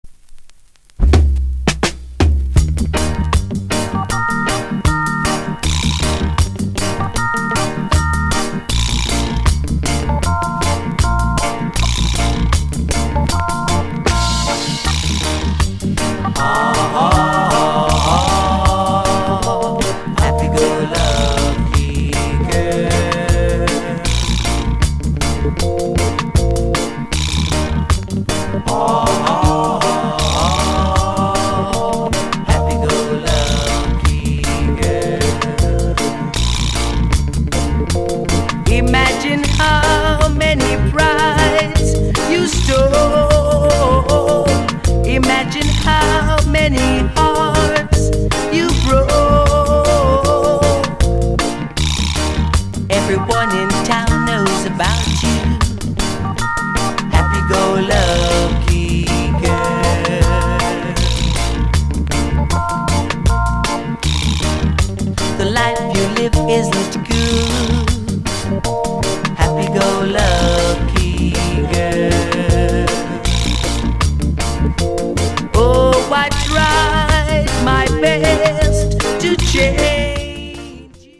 Genre Reggae70sLate / [A] Group Vocal Male Vocal [B] Male DJ